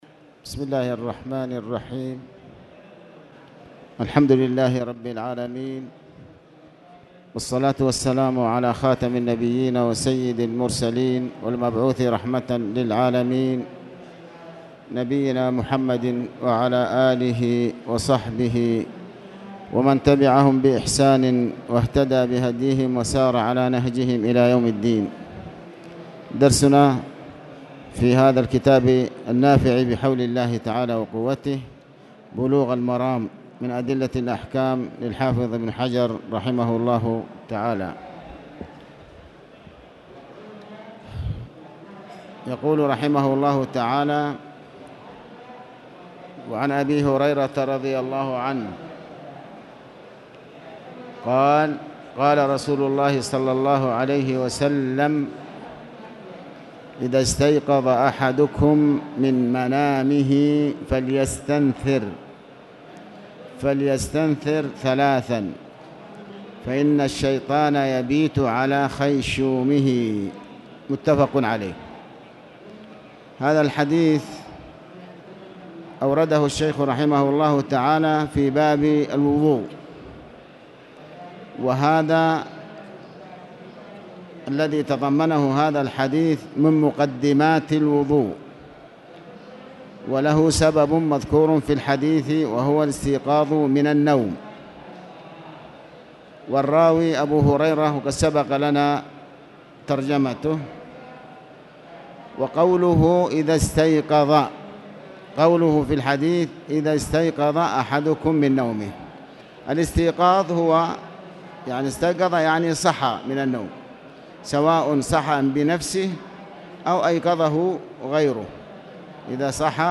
تاريخ النشر ١٤ ربيع الثاني ١٤٣٨ هـ المكان: المسجد الحرام الشيخ